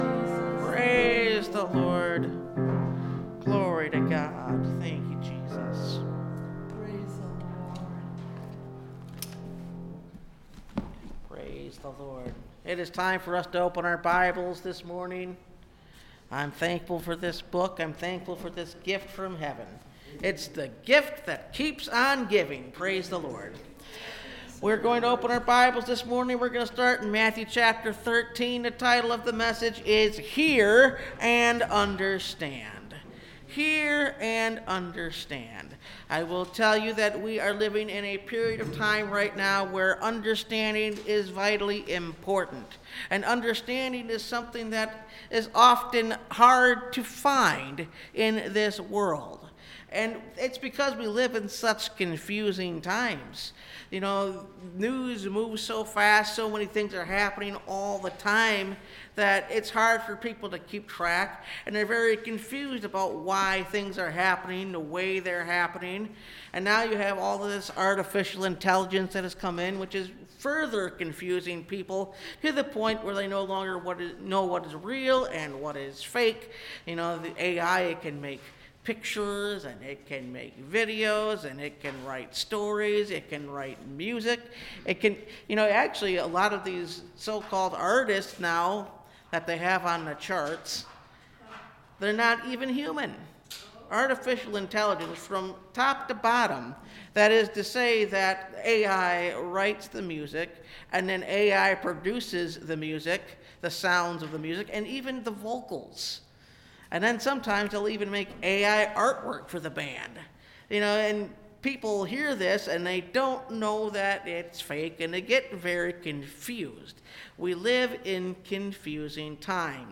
Hear and Understand (Message Audio) – Last Trumpet Ministries – Truth Tabernacle – Sermon Library